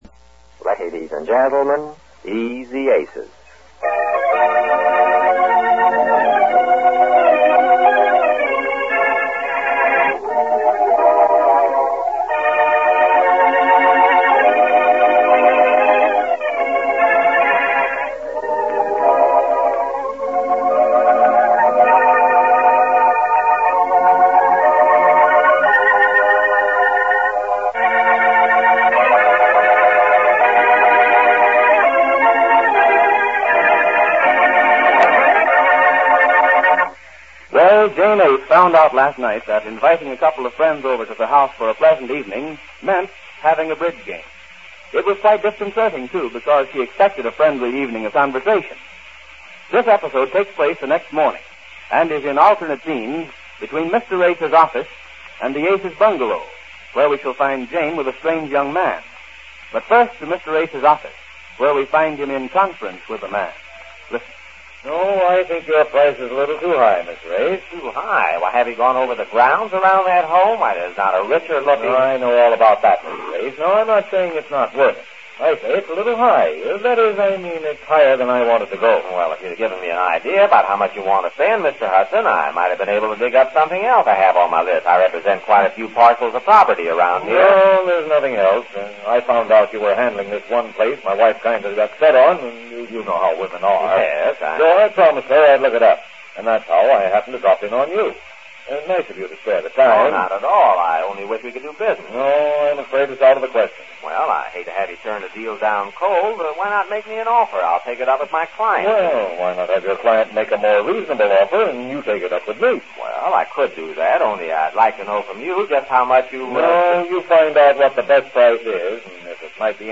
Easy Aces Radio Program, Starring Goodman Aiskowitz and Jane Epstein-Aiskowitz